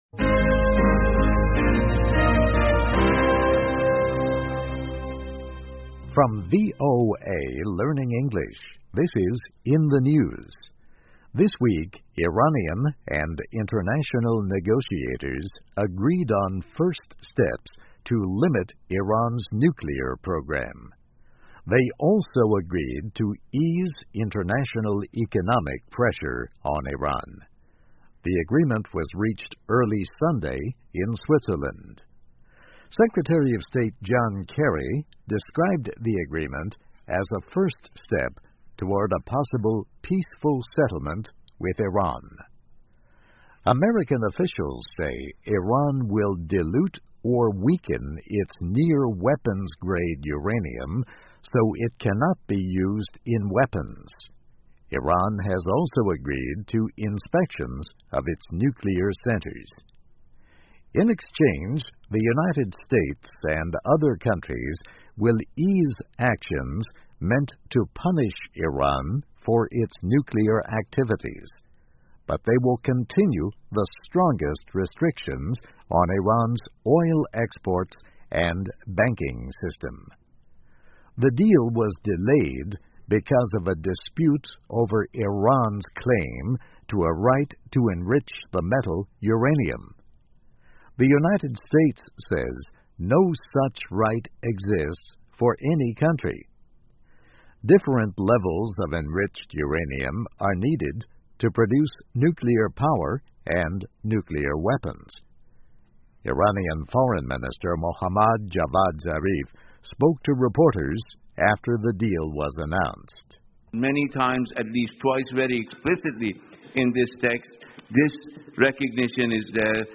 VOA慢速英语2013 伊朗核协议迈出成功的第一步 听力文件下载—在线英语听力室